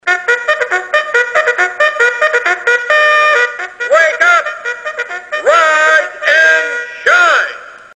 a "Speak Up" alarm clock from the 90s